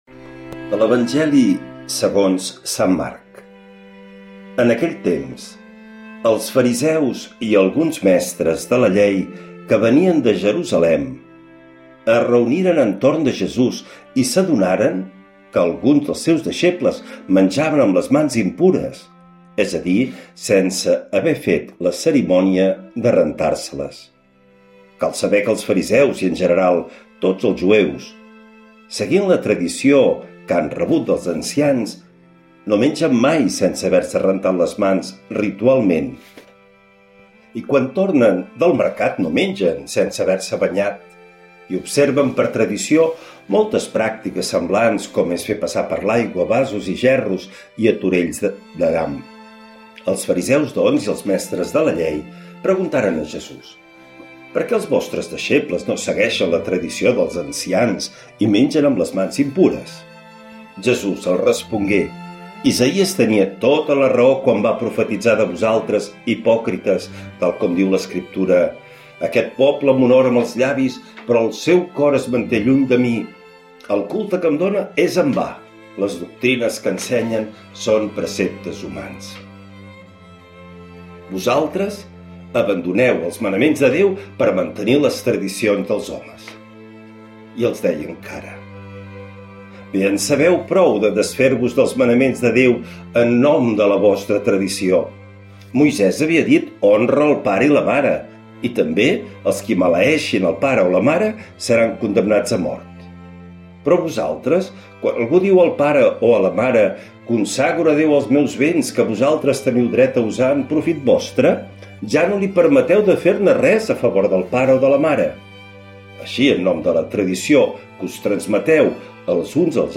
L’Evangeli i el comentari de dimarts 10 de febrer del 2026.
Lectura de l’Evangeli segons Sant Marc.